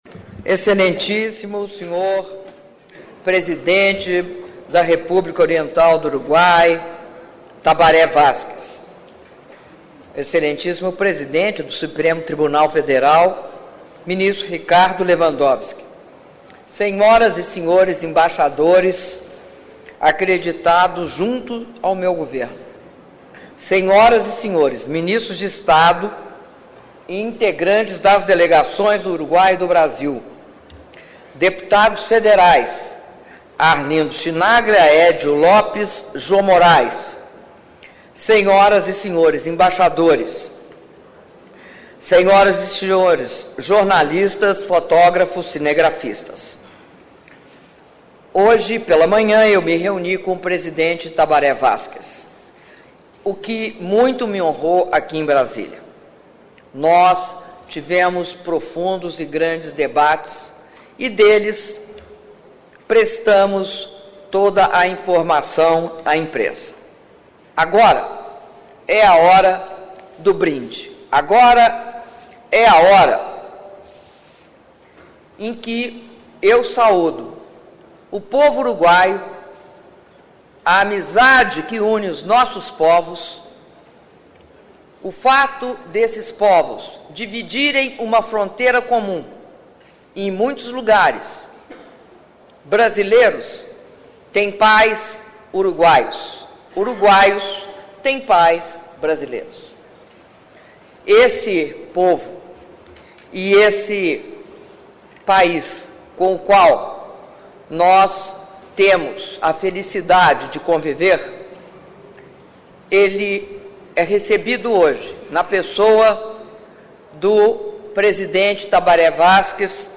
Áudio do brinde da Presidenta da República, Dilma Rousseff, durante almoço em homenagem ao Presidente do Uruguai, Tabaré Vázquez - Brasília/DF (03min19s)